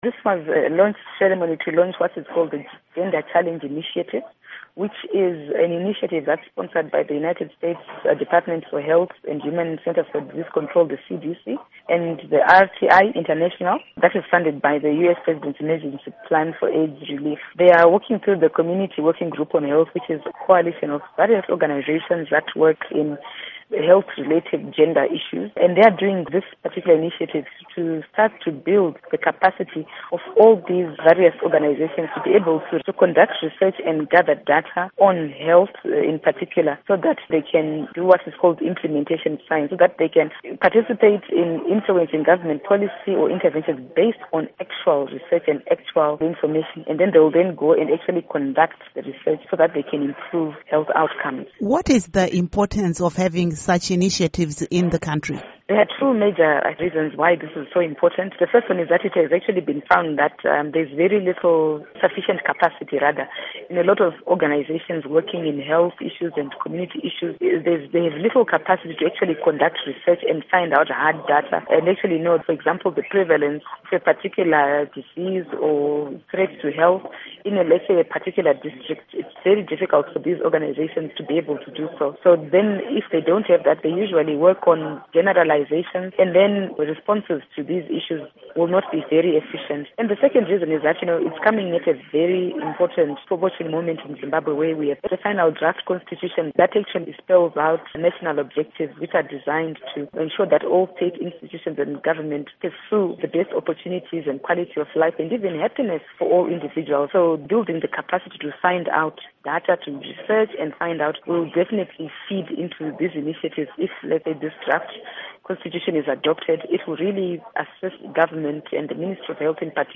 Interview With Jessie Majome